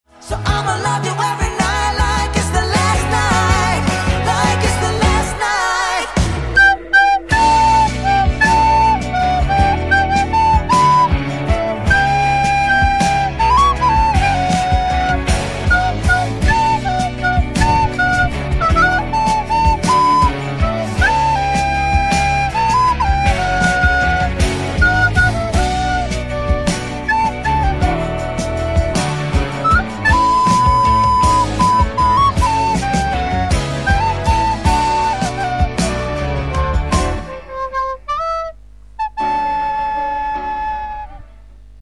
Funny Ringtones